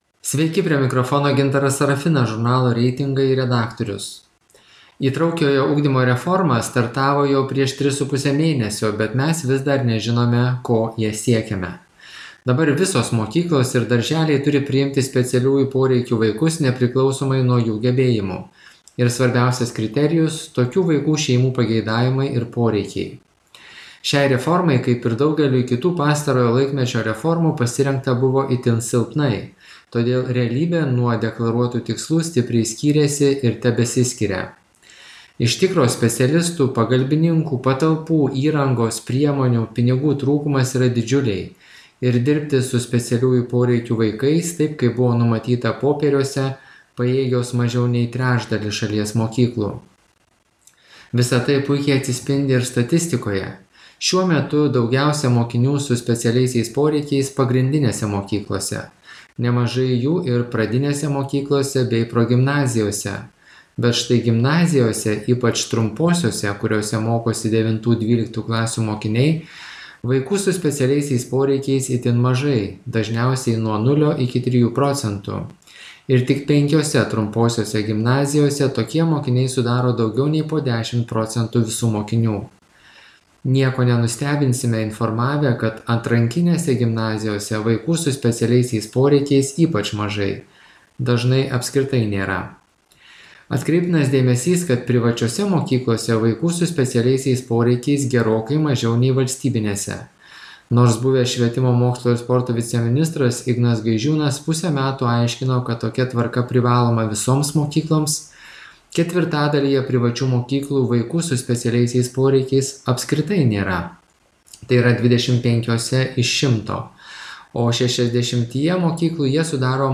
Žiniasklaidos komentaras